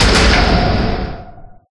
Door7.ogg